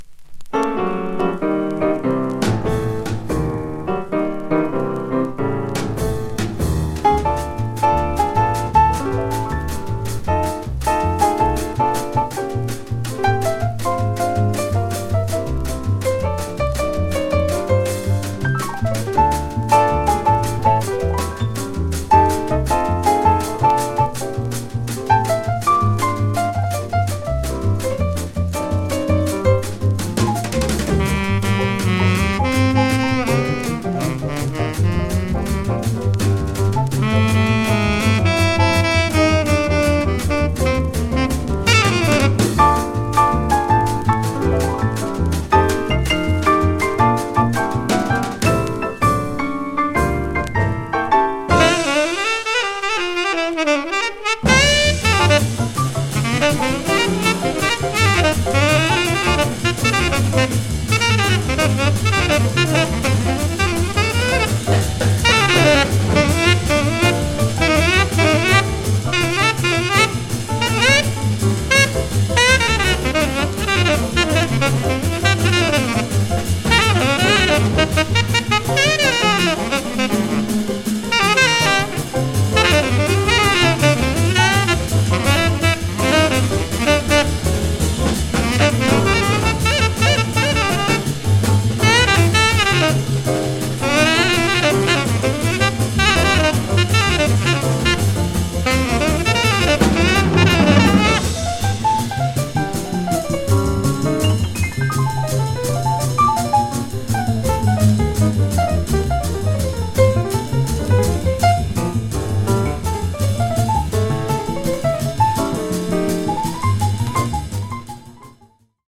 軽快で心地良い
日本のモダンジャズ黎明期の貴重な記録です。
NotesMono